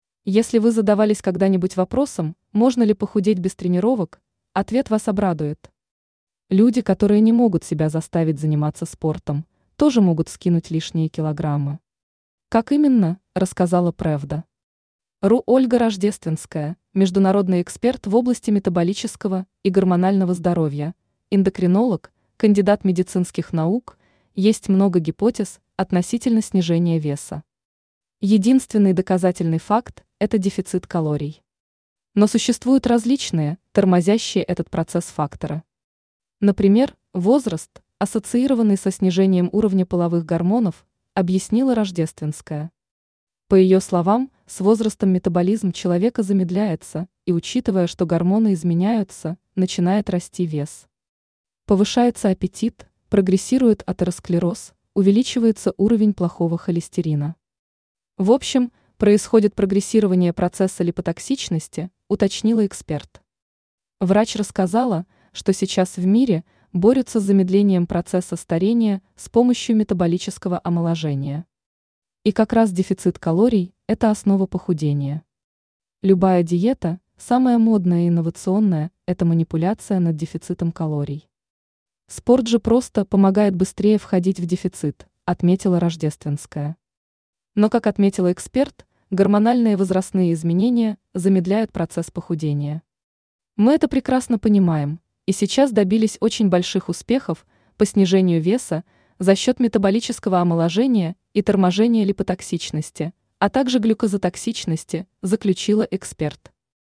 скачать интервью в txt формате